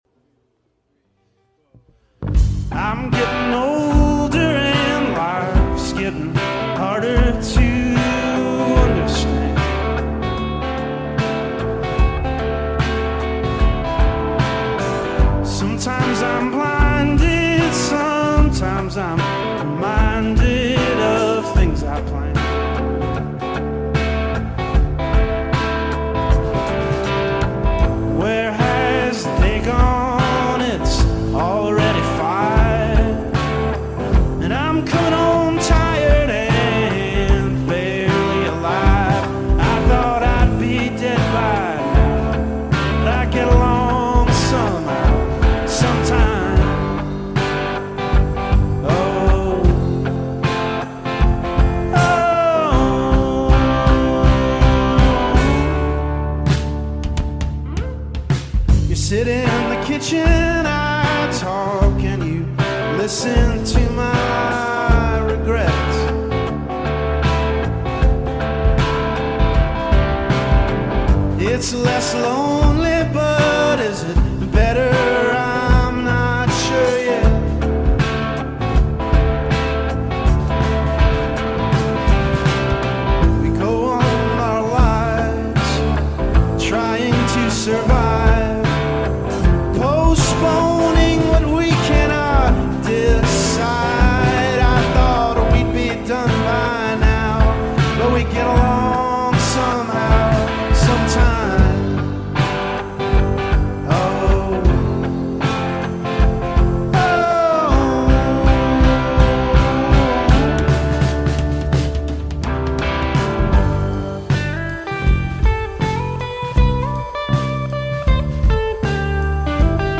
at McGonigels Mucky Duck Houston, TX